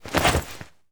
foley_jump_movement_throw_06.wav